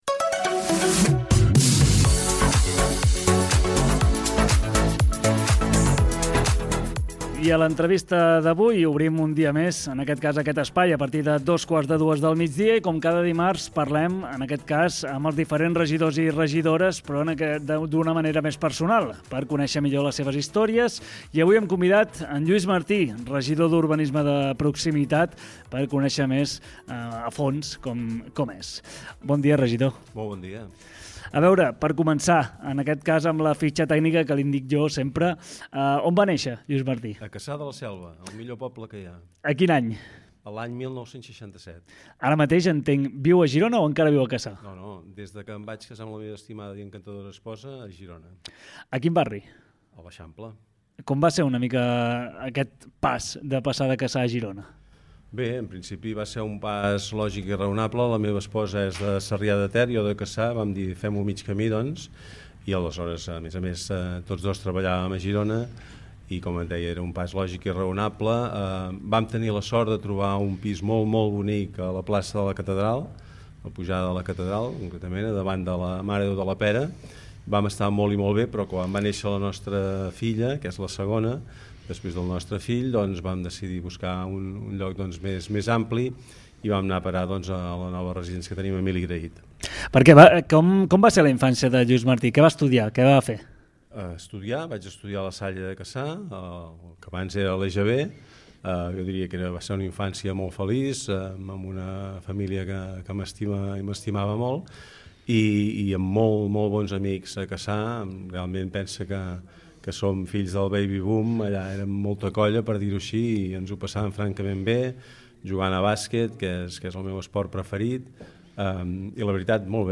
Entrevista personal al Regidor d’Urbanisme de Proximitat Lluís Martí a GironaFM